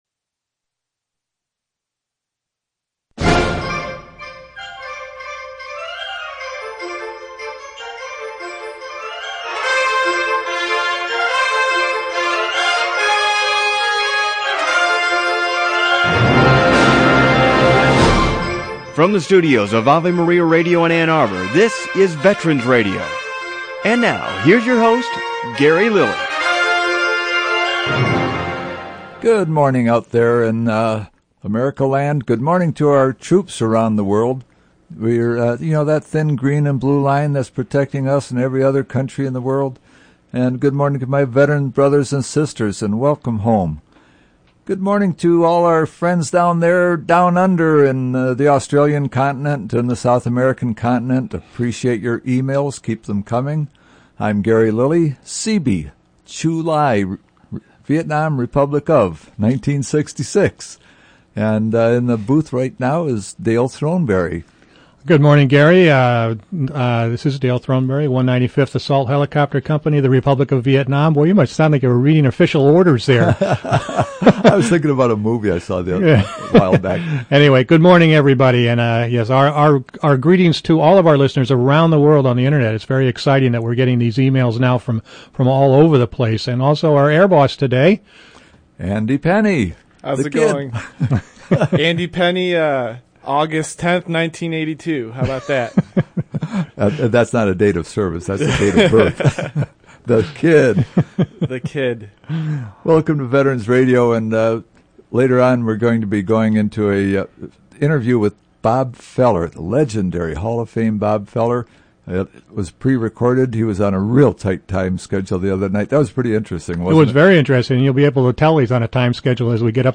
guest Bob Feller, the only retired Navy Chief who is a member of a major sports Hall of Fame.